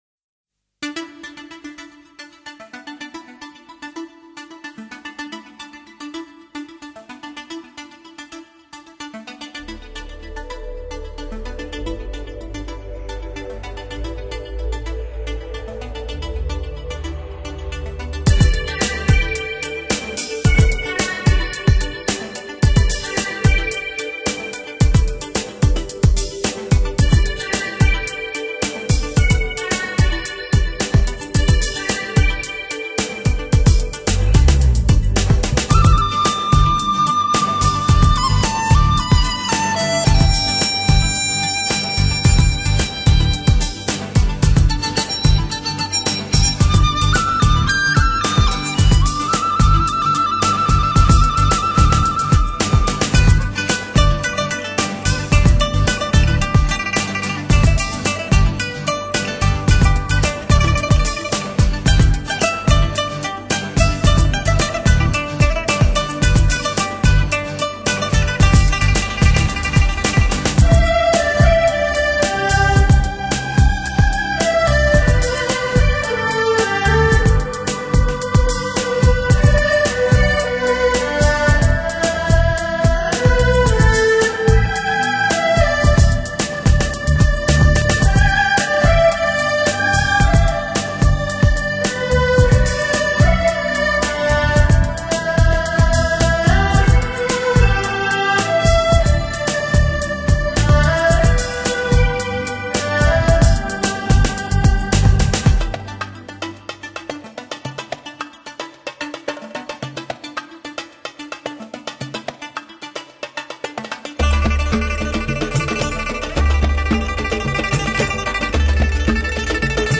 中国原创新世纪音乐作品系列精选,极品人声发烧天碟,极度发烧的真谛....